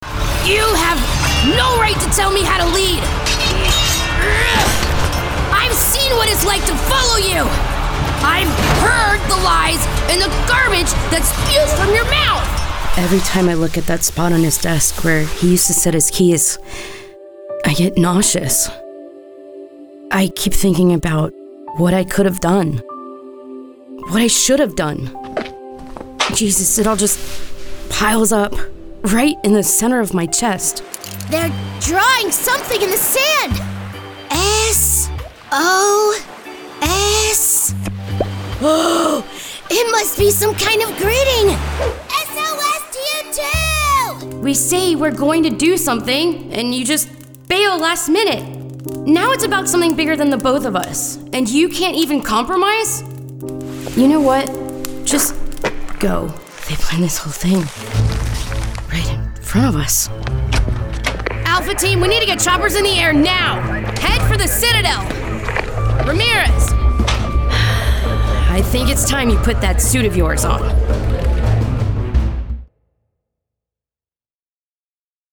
She can provide both grounded and animated reads.
Child, Teenager, Young Adult, Adult
Professional, broadcast-ready home studio, located in Orlando, FL, USA.
VOICEOVER GENRE ANIMATION 🎬